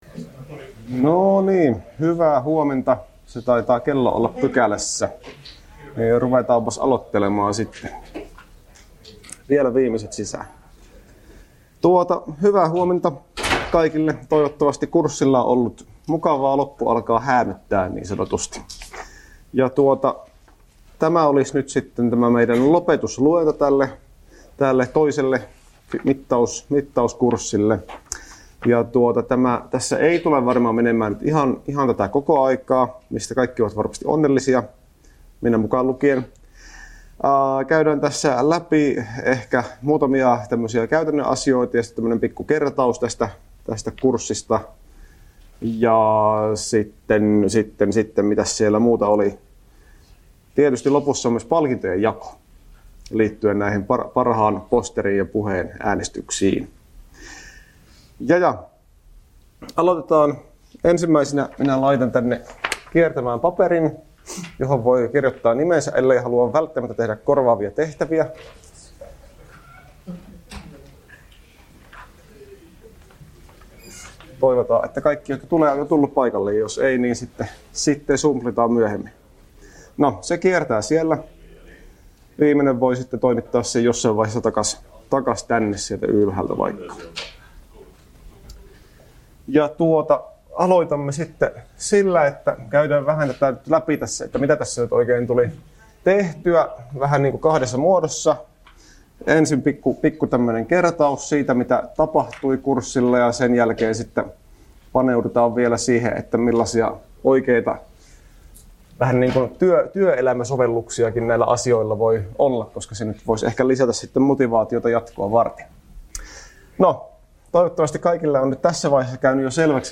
Lopetusluento — Moniviestin